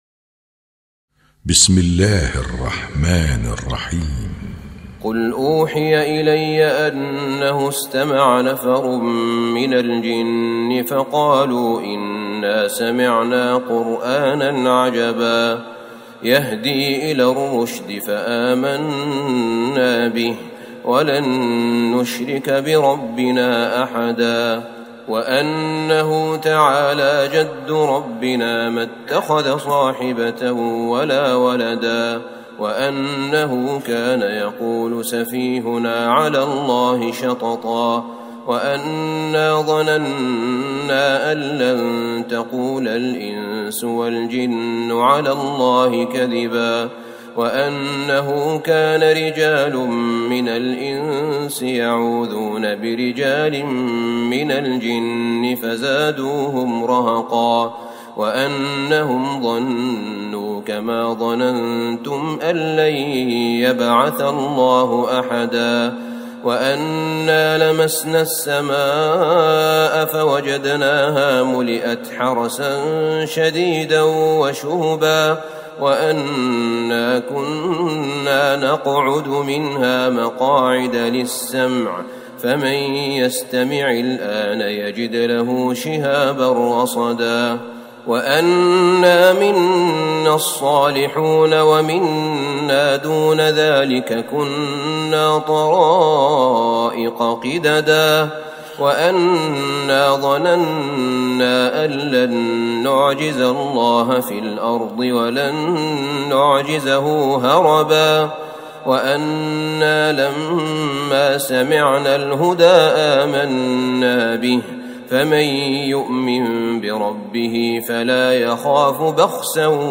سورة الجن Surat Al-Jinn > مصحف تراويح الحرم النبوي عام 1440هـ > المصحف - تلاوات الحرمين